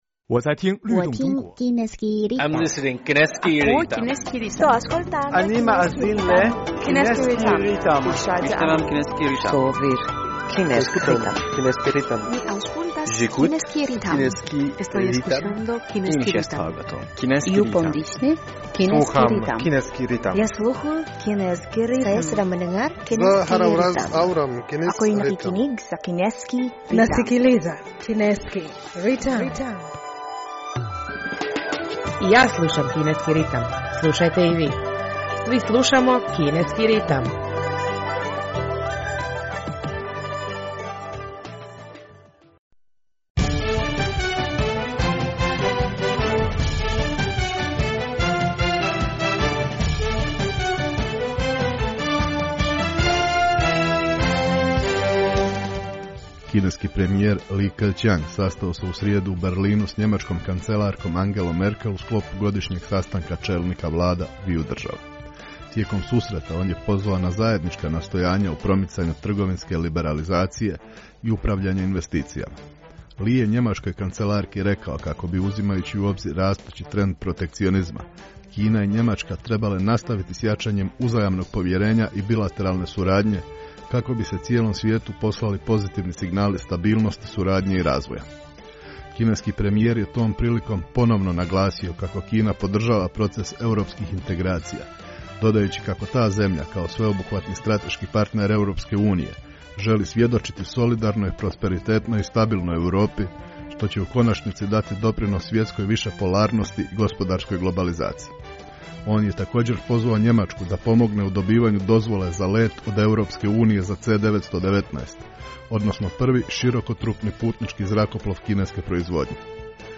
U današnjoj emisiji prije svega poslušajte novosti iz Kine i svijeta, a zatim našu rubriku "U fokusu Kine".